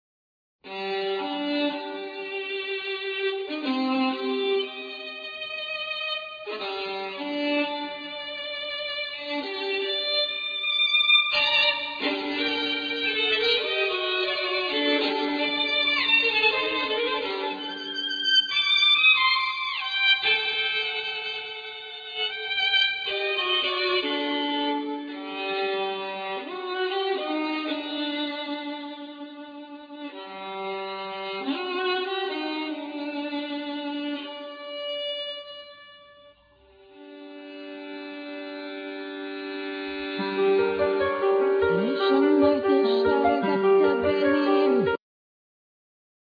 Piano,Keyboards,Vocals
Double bass
Violin,Viola
Bassoon
Cello